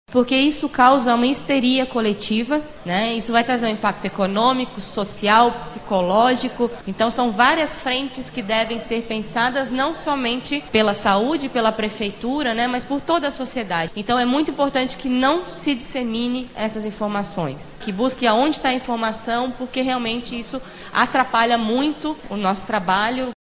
O assunto foi um dos pontos abordados na audiência realizada na Câmara Municipal, nesta quarta-feira, 18.